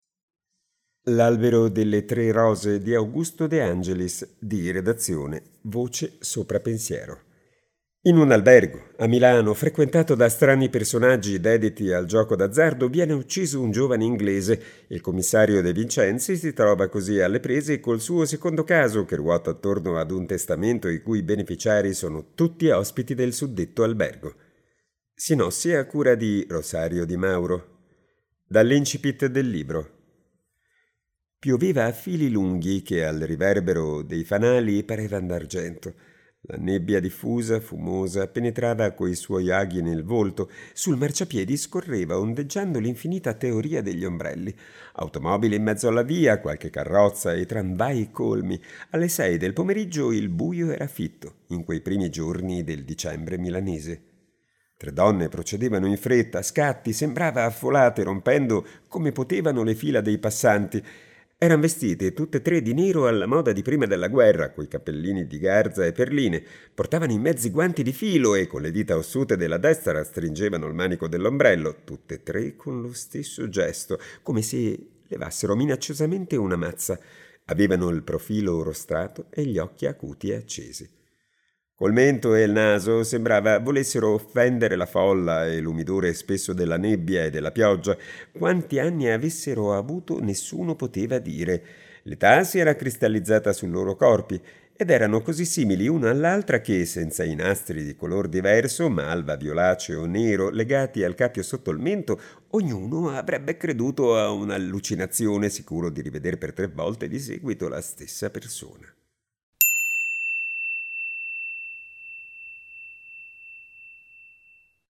Dall’incipit del libro: